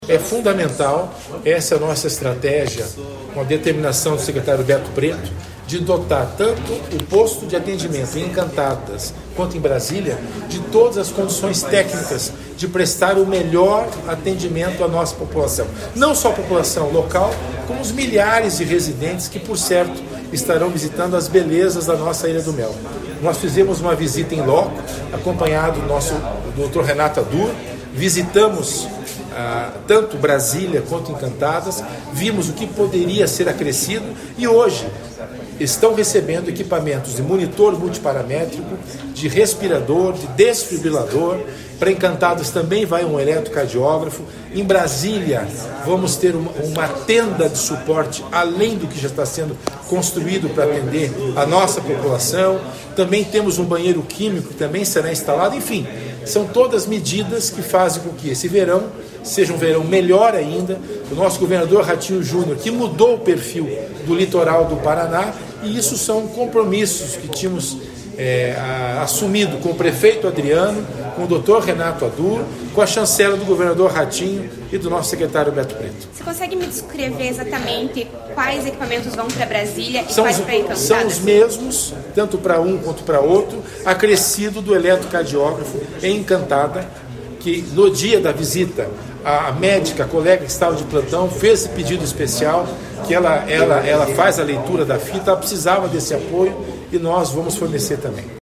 Sonora do secretário da Saúde em exercício, César Neves, sobre os novos equipamentos para saúde pública da Ilha do Mel